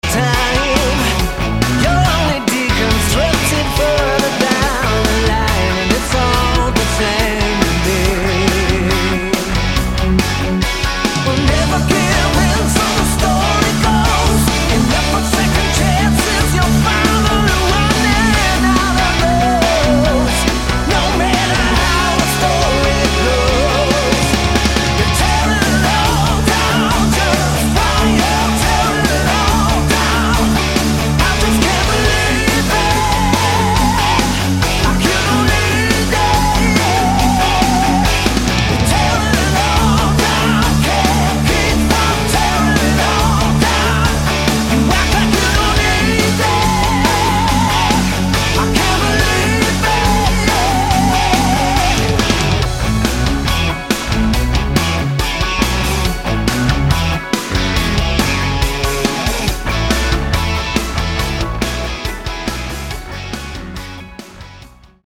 Lead vocals and backing vocals
Lead Guitars, Rhythm Guitars and Keyboards
Bass Guitars
Drums
we have huge choruses
melodic rock